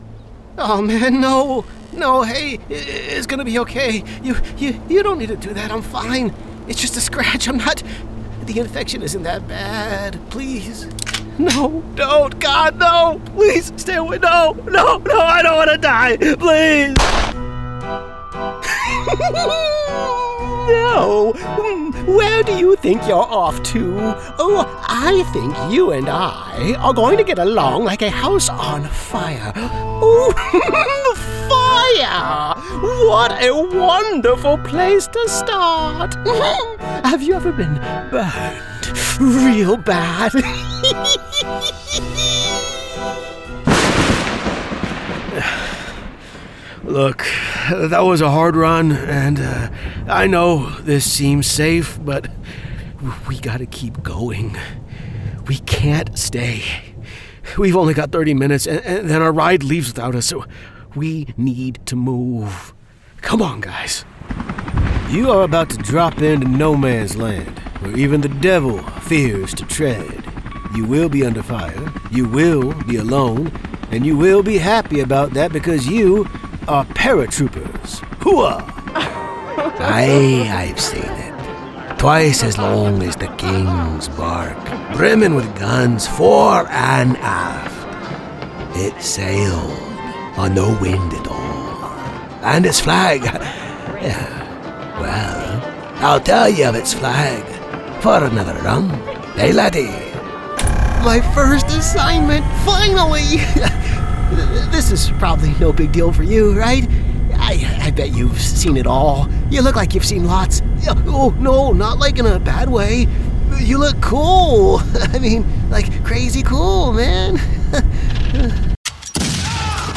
Video Game Characters
Middle Aged
High-Energy | Cool & Edgy | Corporate & Professional
Video Game Demo.mp3